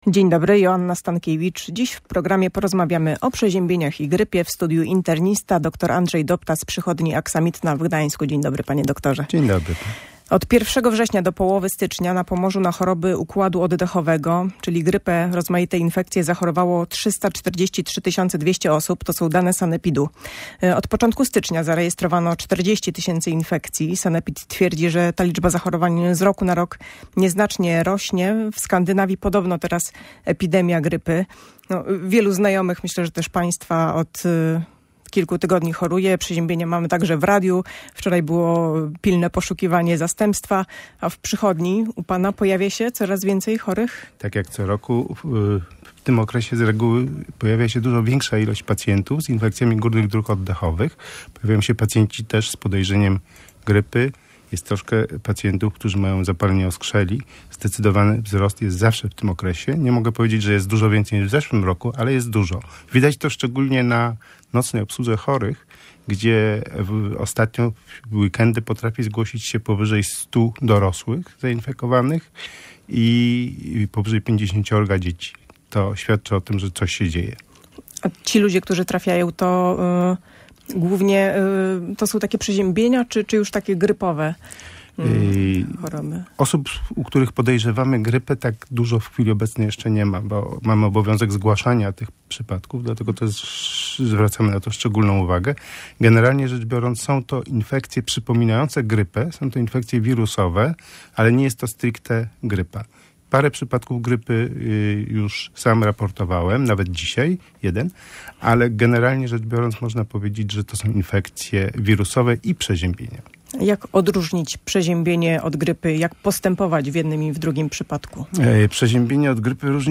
mówił w Radiu Gdańsk